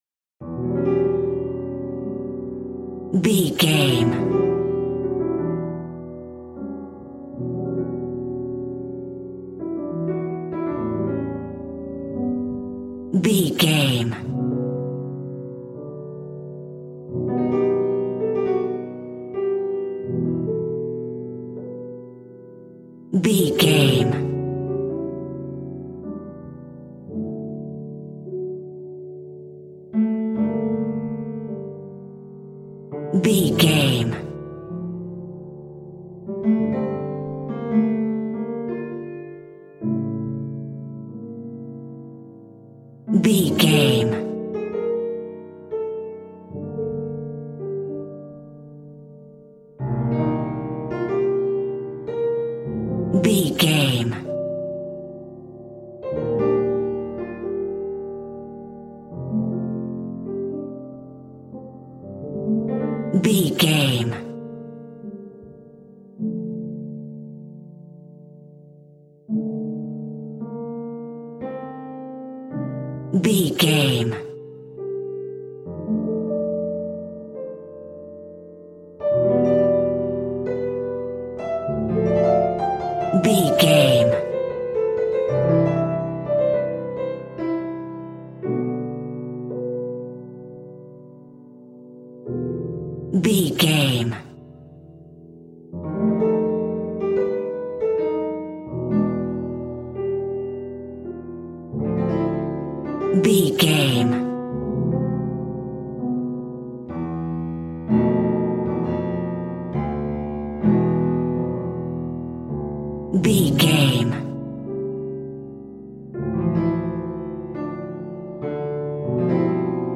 A Classical Horror Music Theme.
Aeolian/Minor
Slow
tension
ominous
dark
haunting
creepy